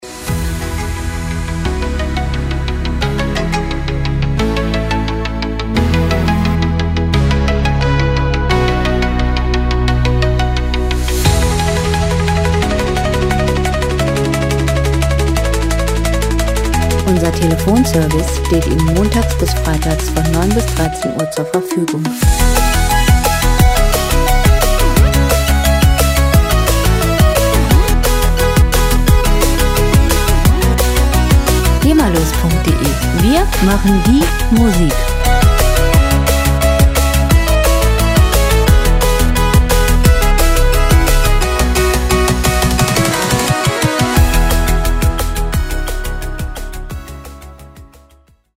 • Electro-Pop